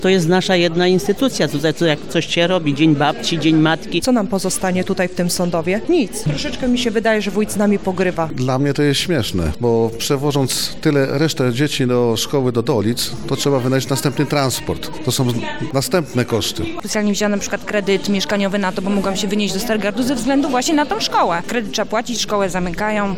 mieszkańcy sądowa.mp3